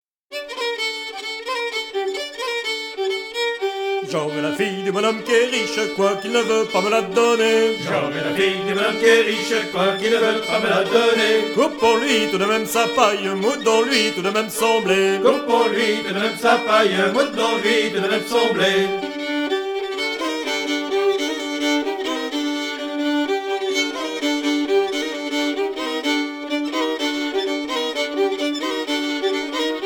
Divertissements d'adultes - Couplets à danser
danse : branle
Pièce musicale éditée